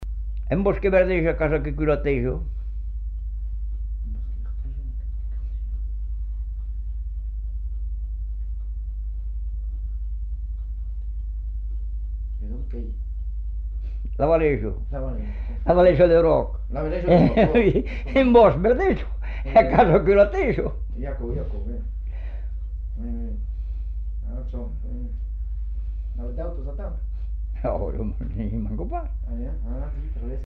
Aire culturelle : Savès
Département : Gers
Genre : forme brève
Effectif : 1
Type de voix : voix d'homme
Production du son : récité
Classification : devinette-énigme